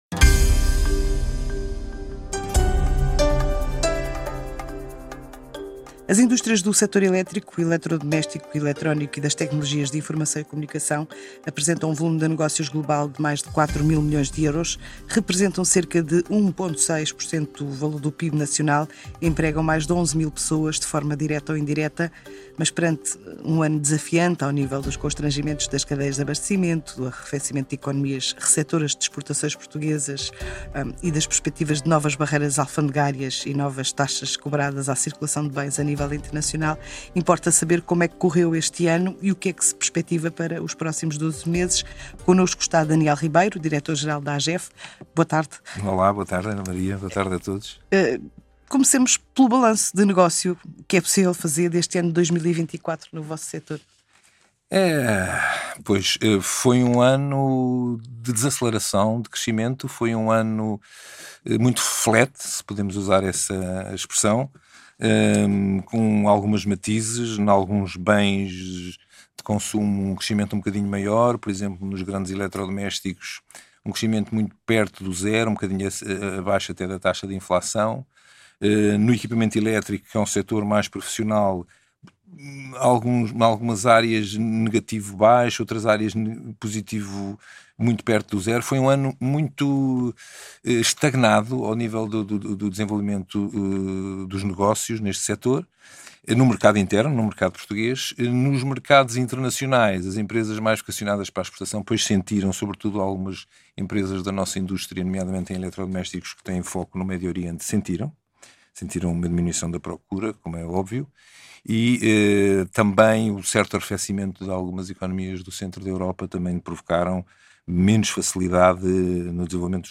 Entrevista DR_Negócios em Português.mp3